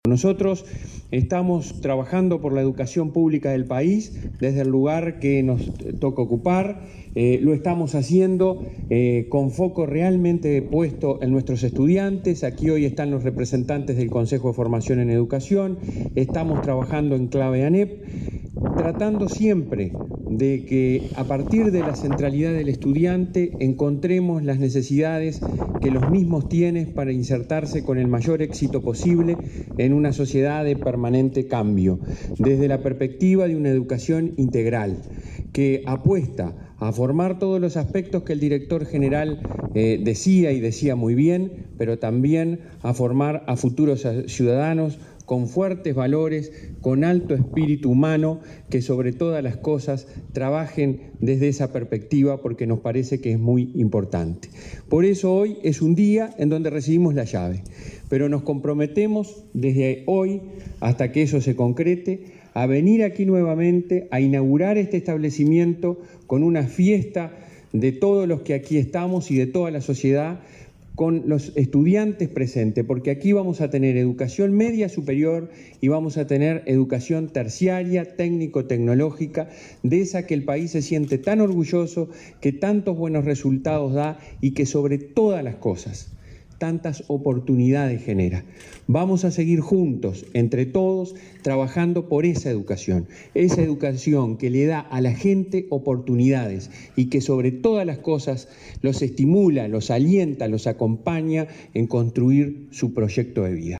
Declaraciones del presidente del Codicen, Robert Silva
La ANEP entregó obras en en Canelones y Montevideo. Silva participó del evento.